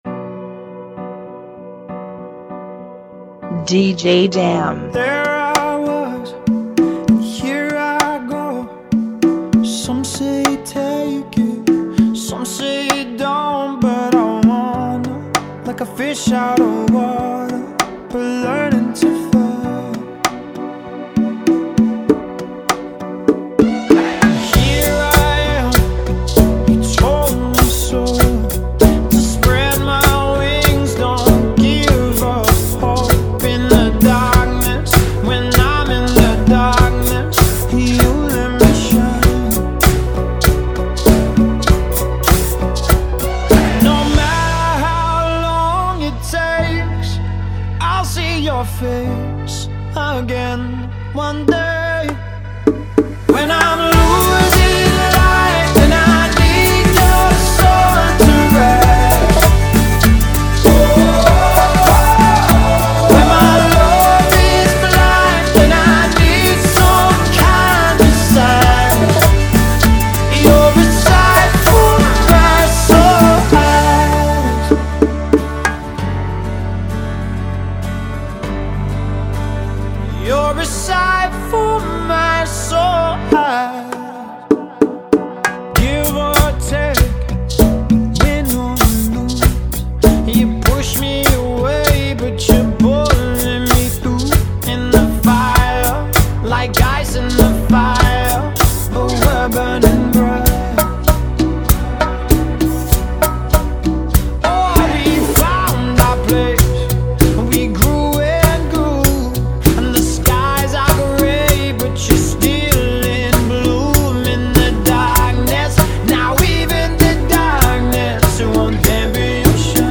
97 BPM
Genre: Bachata Remix